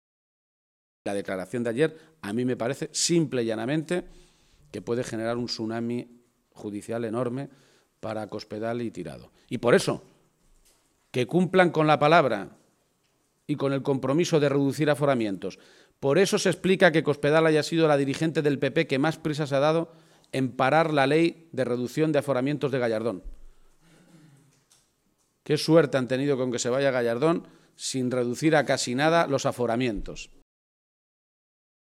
García-Page ha hecho este anuncio en la misma comparecencia ante los medios de comunicación en la que ha comprometido un programa para garantizar un empleo y unos ingresos al nivel del salario mínimo para todos los parados de larga duración mayores de 55 años.